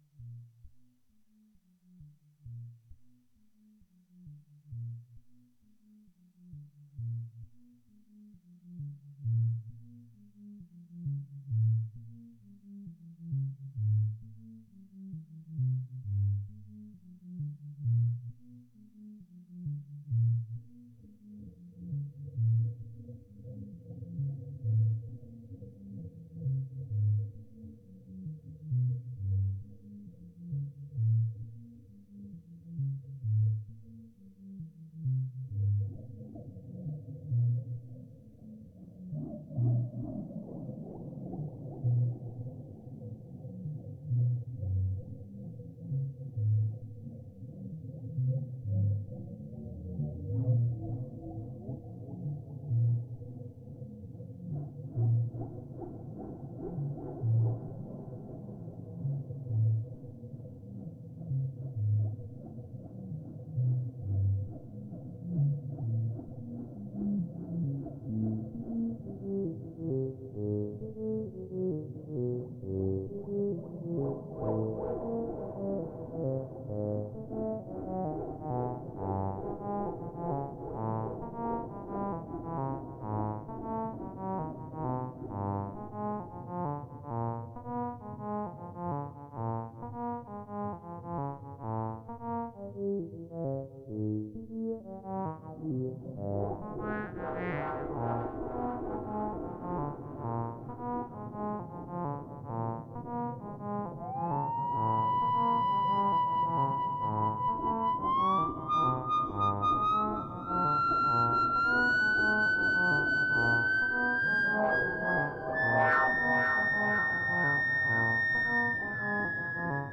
Etrange, loufoque, surement des curiosités extraterrestres.